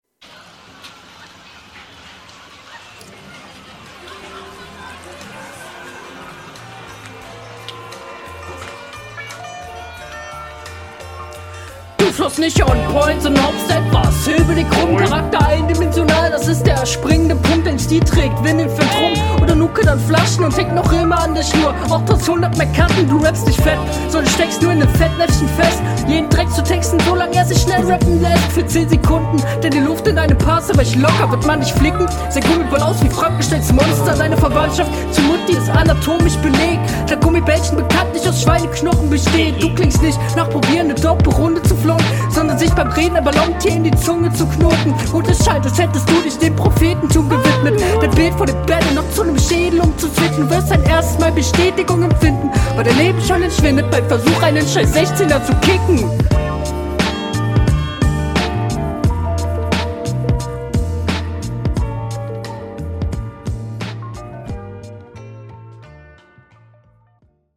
Ja ok auf seinem Beat funktioniert die Stimme deutlich besser.
das klingt zu sehr nach einem onetake der kein onetake hätte werden sollen (fr was …